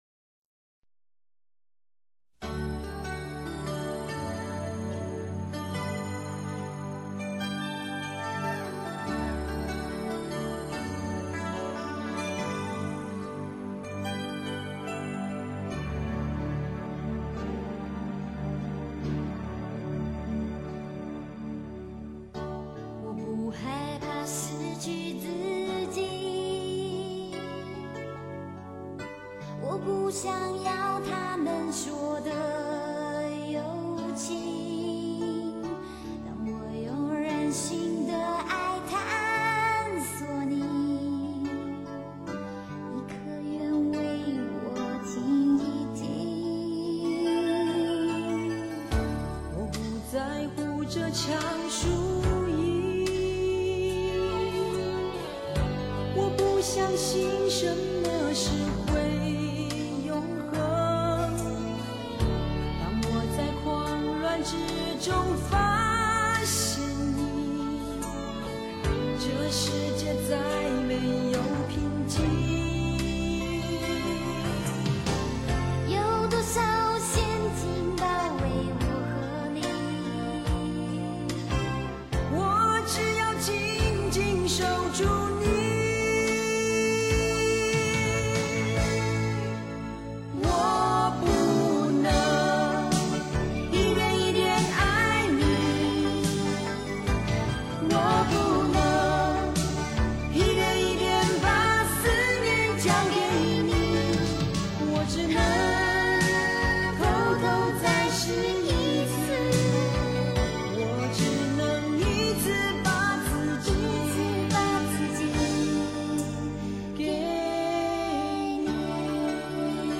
但这是一首男女对唱歌曲，本来就是一个特殊的声音再加入了一个特殊的女声，让人听了好觉可爱，就如两个未成年的孩儿在唱着情歌。